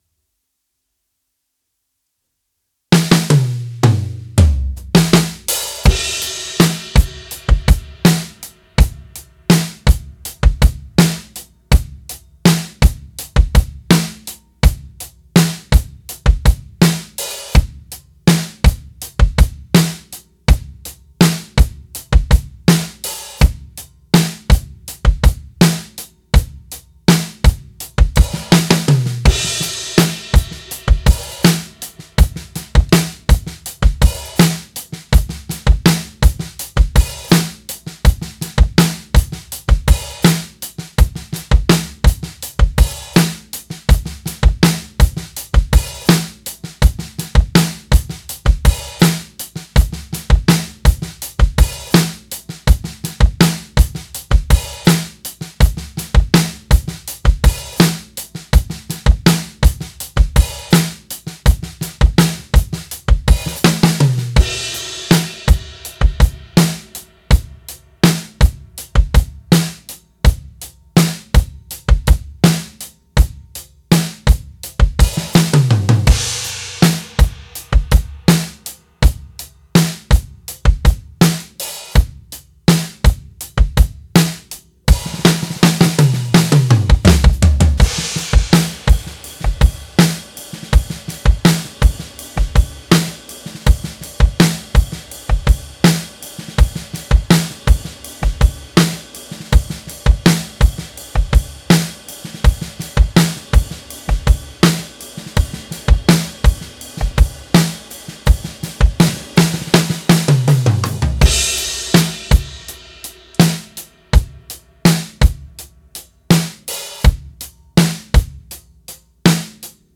Pop
Genre:Pop, Country
Tempo:82 BPM (4/4)
Kit:Yamaha Birch Custom Absolute 20"
Mics:15 channels